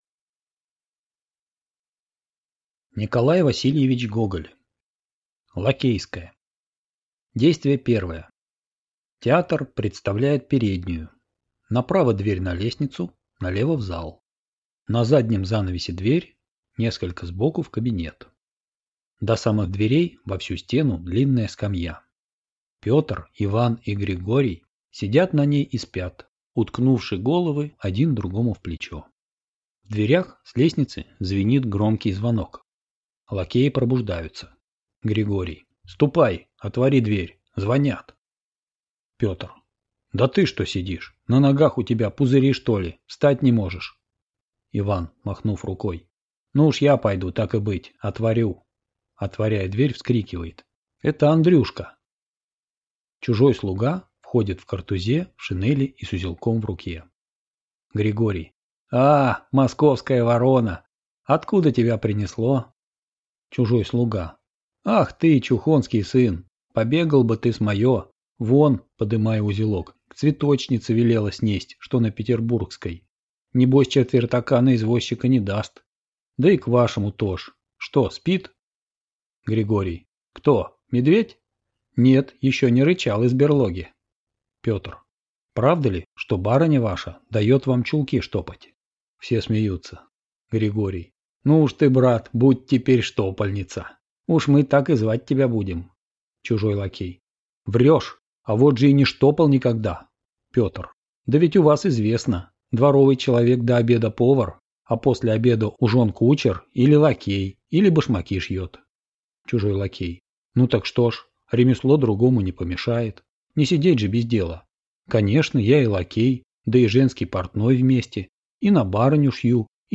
Аудиокнига Лакейская | Библиотека аудиокниг